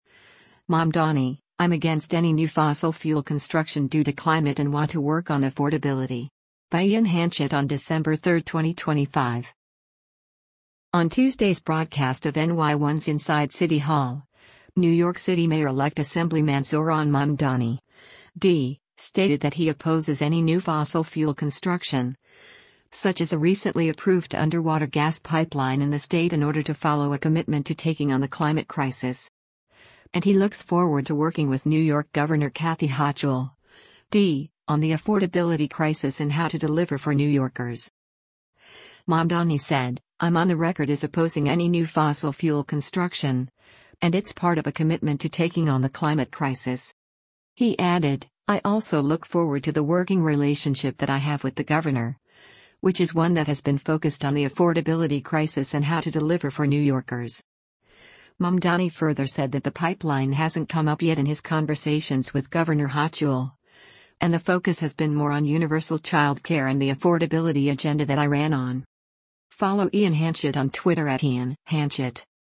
On Tuesday’s broadcast of NY1’s “Inside City Hall,” New York City Mayor-Elect Assemblyman Zohran Mamdani (D) stated that he opposes any new fossil fuel construction, such as a recently approved underwater gas pipeline in the state in order to follow “a commitment to taking on the climate crisis.”